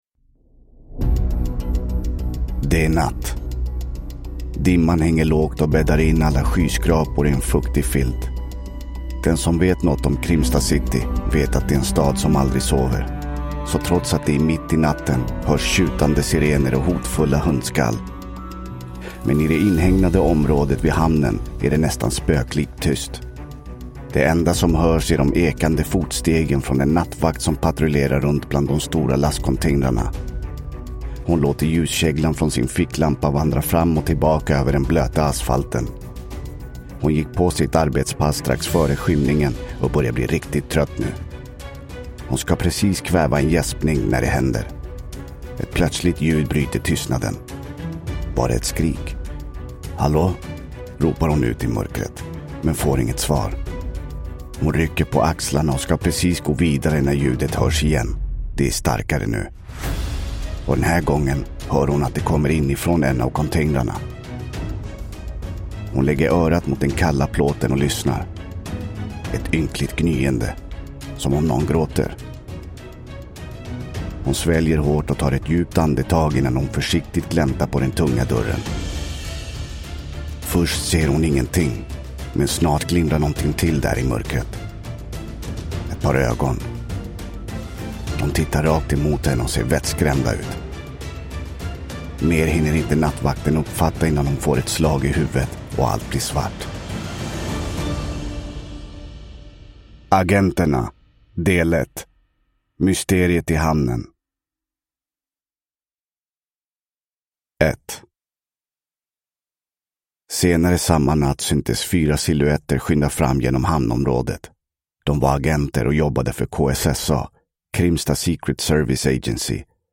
Mysteriet i hamnen (ljudbok) av Karin Aspenström